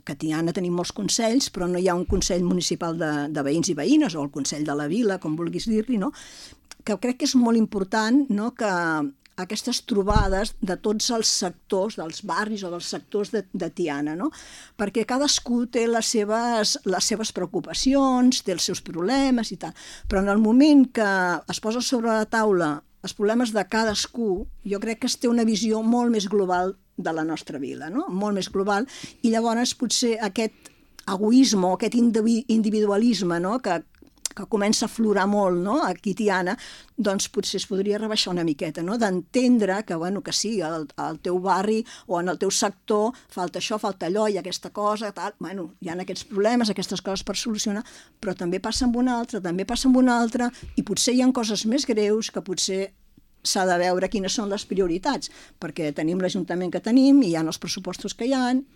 Un any després de la seva primera entrevista com a Síndica de Greuges, la Cel·la Fort torna a La Local per fer balanç del seu primer any al càrrec i comentar l’informe que va presentar en el ple del mes de gener.